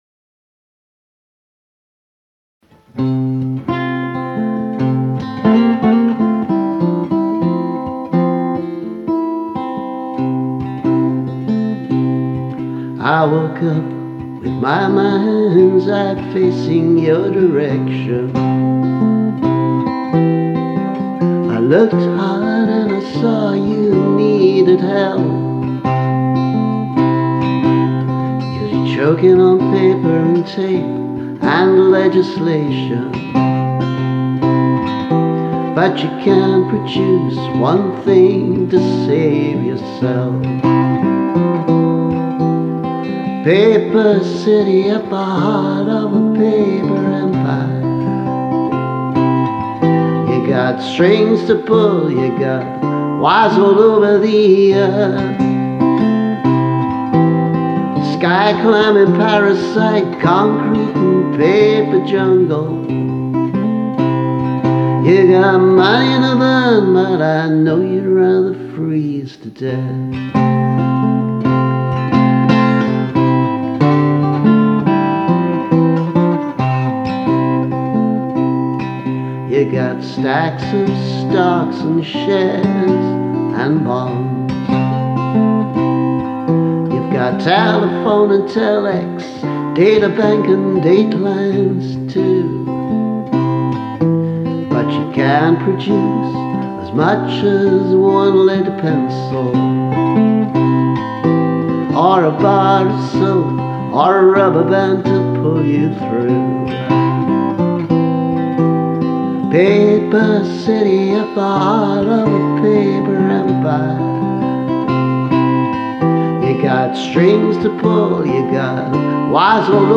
2019 demo version